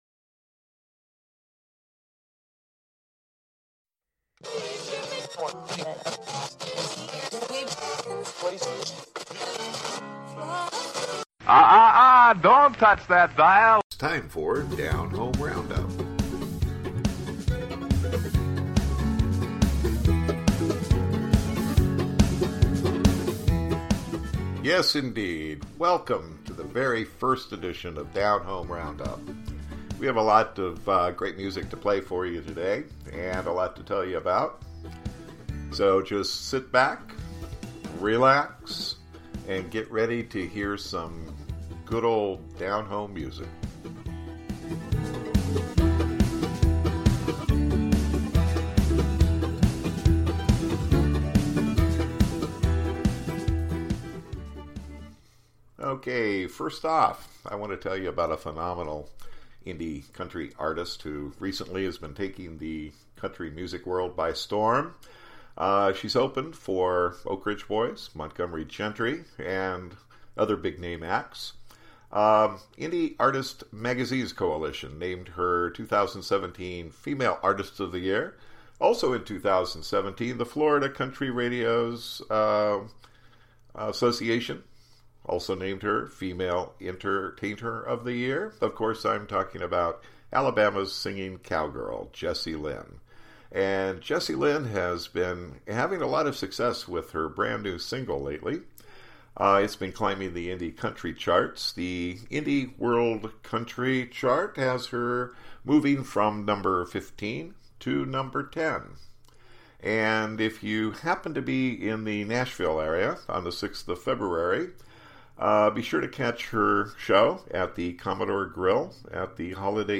Indie Country